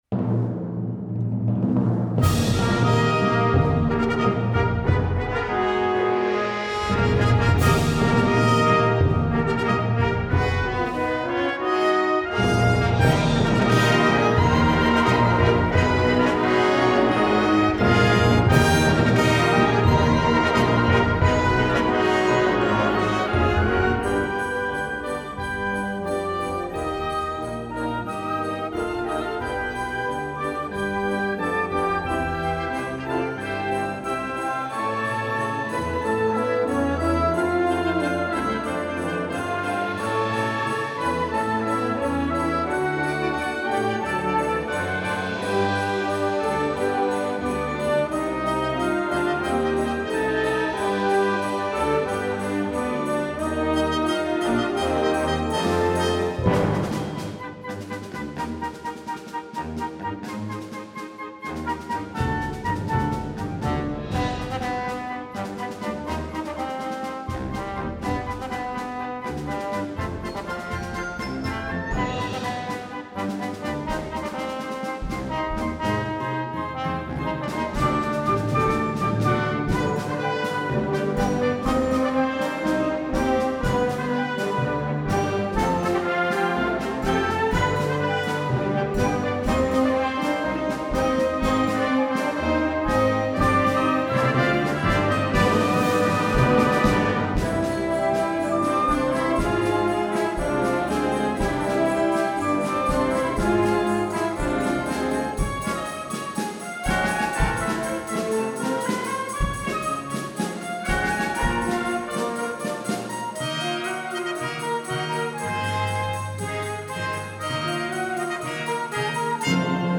Festliche Overtüre für Blasorchester